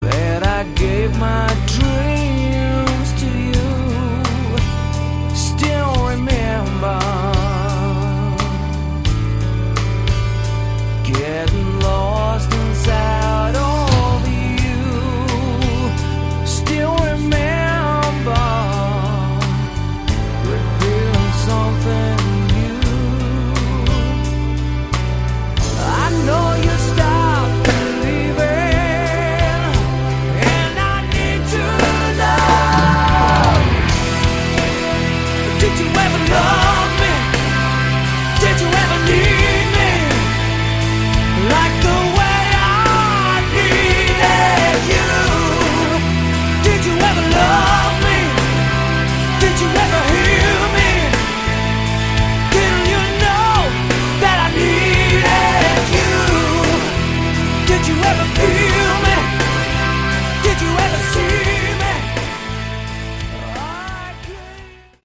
Category: Melodic Prog Metal
Fantastic voice!